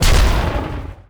weapon_mortar_002.wav